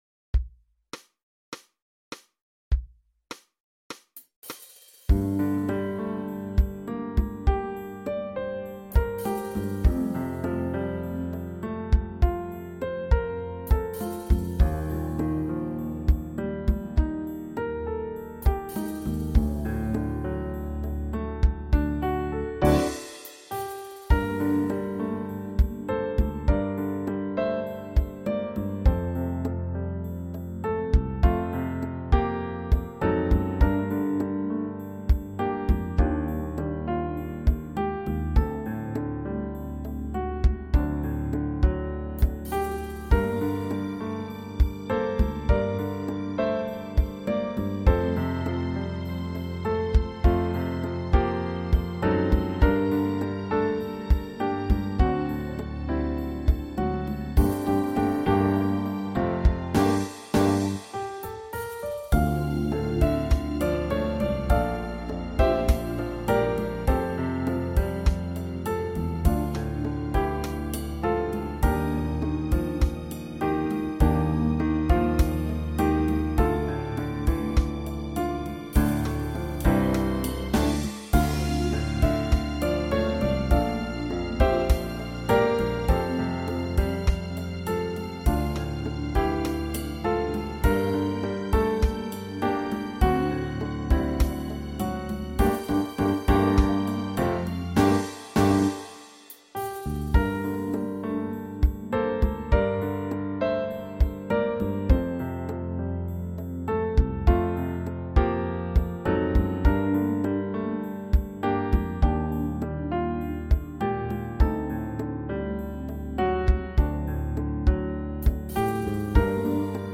(practice)